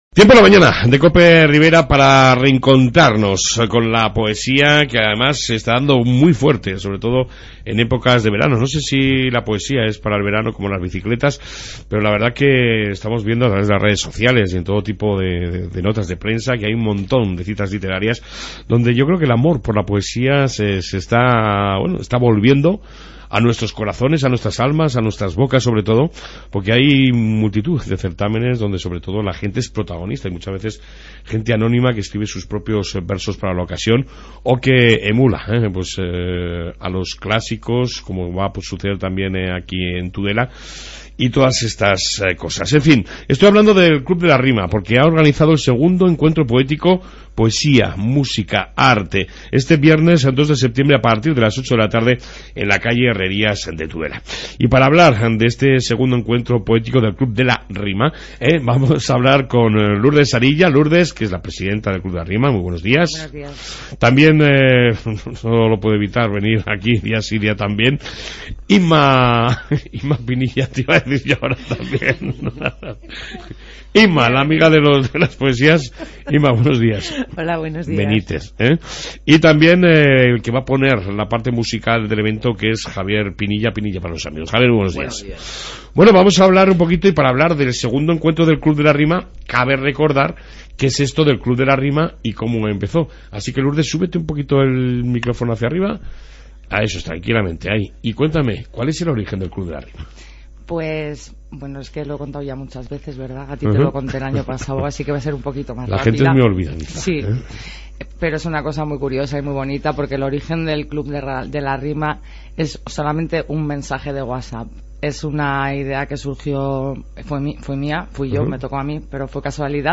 AUDIO: Entrevista con El club de la Rima en su segunda puesta de largo...